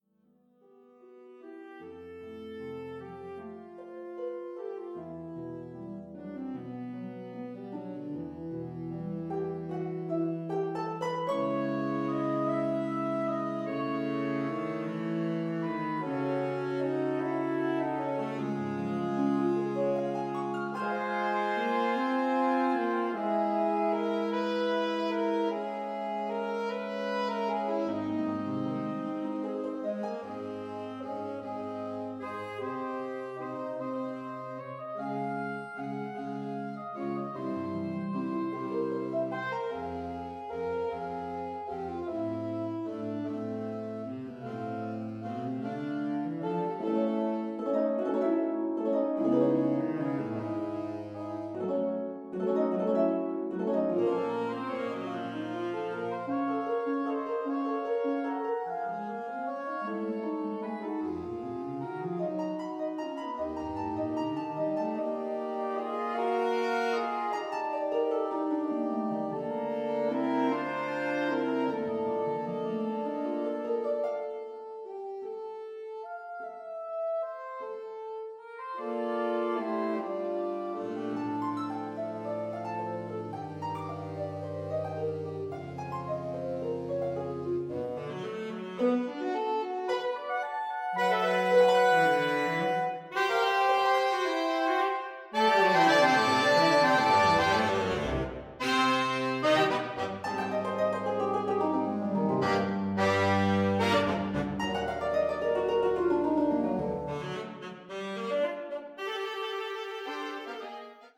A piece for saxophone quartet and harp
which has a strongly celtic flavour.